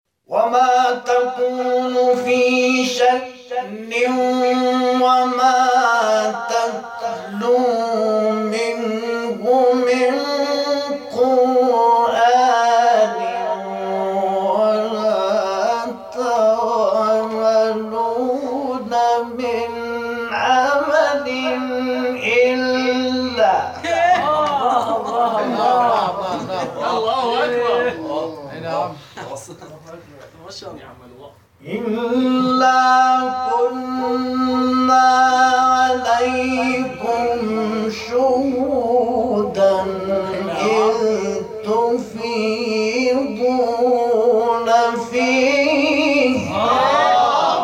گروه شبکه‌های اجتماعی: فرازهای صوتی از تلاوت قاریان ممتاز و بین المللی کشور که در شبکه‌های اجتماعی منتشر شده است، می‌شنوید.